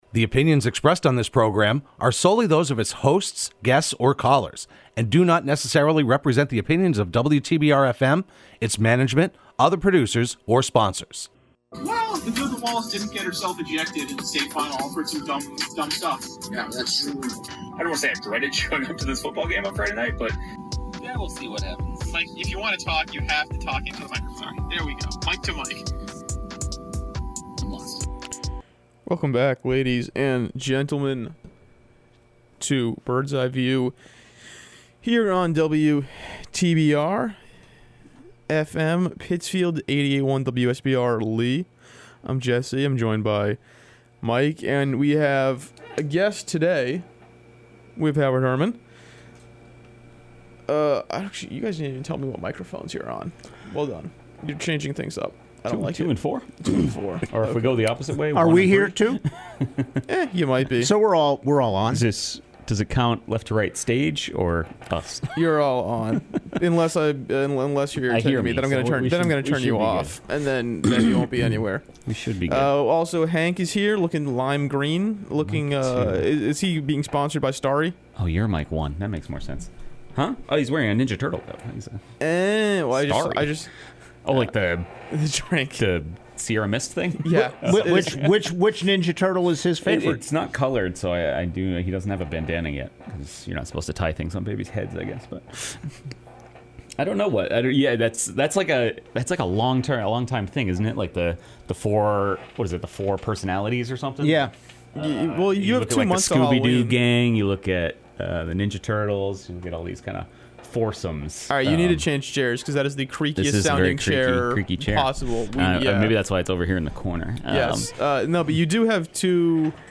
Broadcast live every Thursday morning at 10am on WTBR.